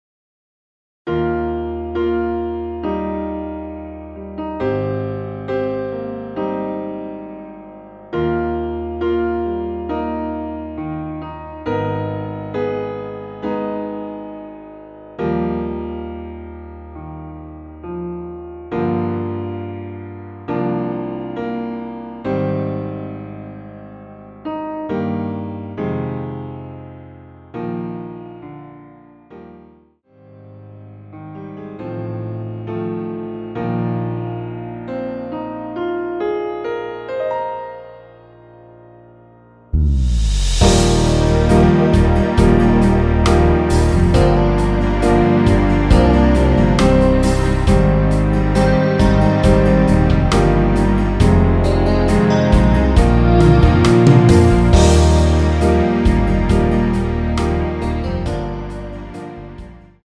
라이브용 버전2절 없이 진행 됩니다.(아래 동영상및 가사 참조)
Eb
음정은 반음정씩 변하게 되며 노래방도 마찬가지로 반음정씩 변하게 됩니다.
앞부분30초, 뒷부분30초씩 편집해서 올려 드리고 있습니다.